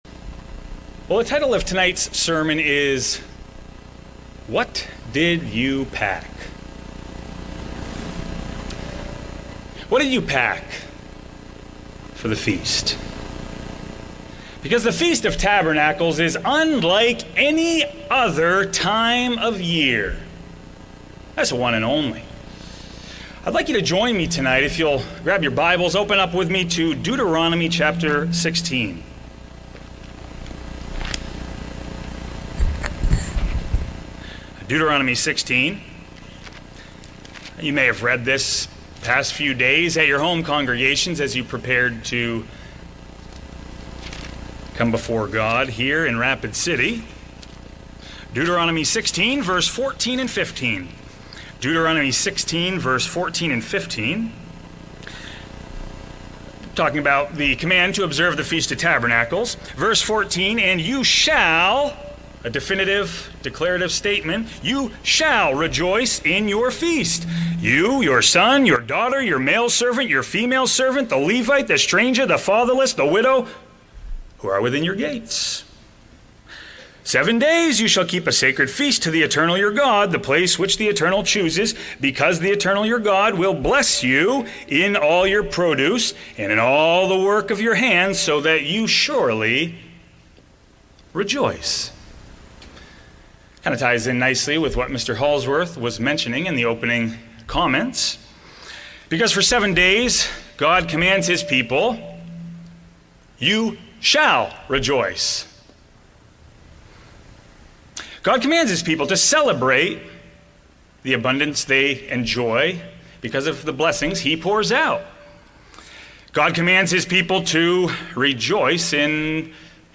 This opening-night message explores how the joy God commands at the Feast (Deut. 16:14-15) doesn’t appear by accident—it depends on the spiritual “luggage” we bring. Drawing parallels to Israel’s pilgrim journeys and Paul’s “wardrobe” of godly character, the sermon shows how preparation, gratitude, and service transform the Feast from mere attendance into worship as we look forward to the coming Kingdom of God!
Given in Rapid City, South Dakota